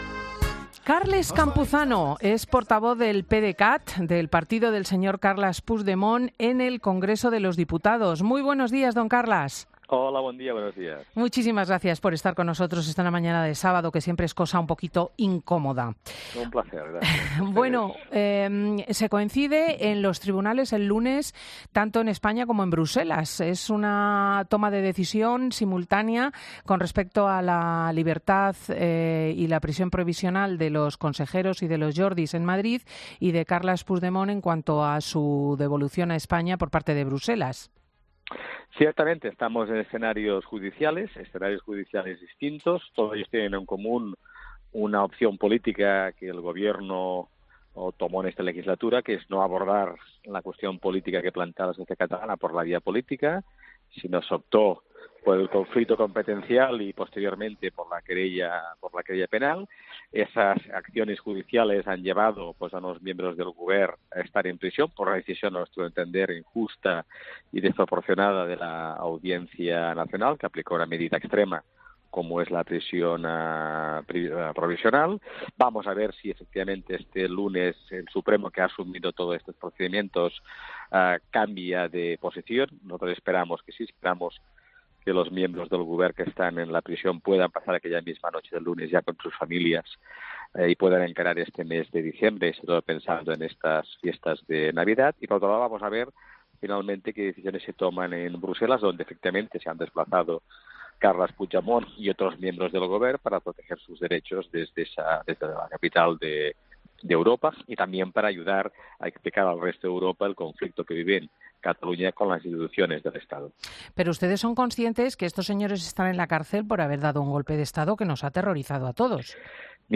Escucha la entrevista a Carles Campuzano, portavoz del PDeCAT, en 'Fin de Semana'